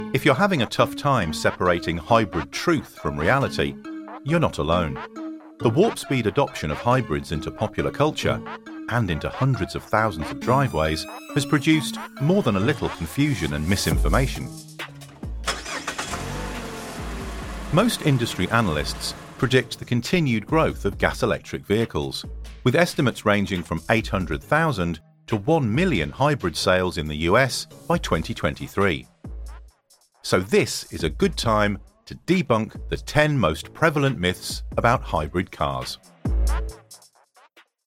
English (British)
Explainer Videos
Mic: SHURE SM7B